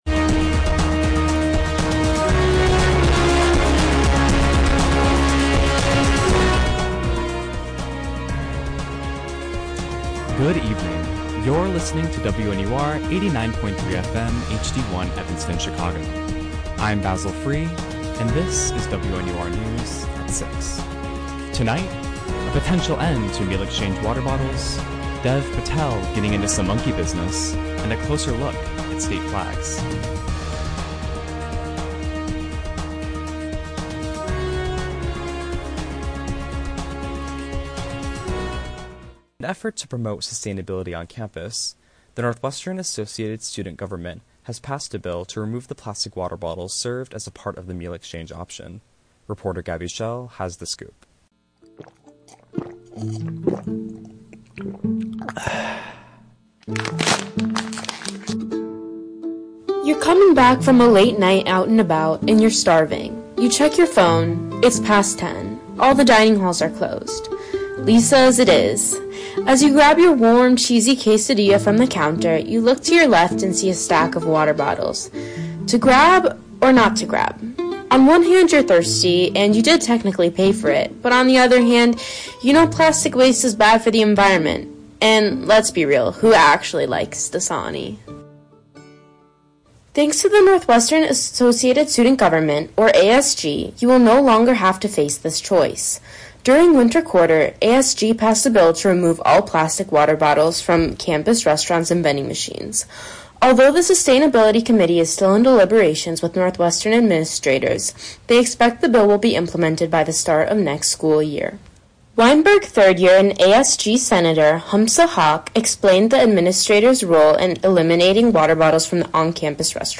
Friday April 19th, 2024: A potential end to meal-exchange water bottles, Dev Patel getting into some monkey business, A closer look at state flags. WNUR News broadcasts live at 6 pm CST on Mondays, Wednesdays, and Fridays on WNUR 89.3 FM.